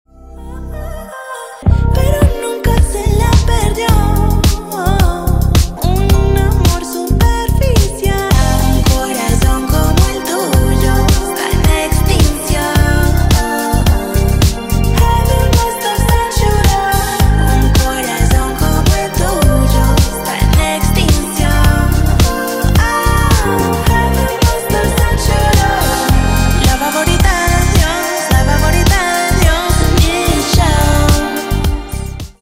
поп , латинские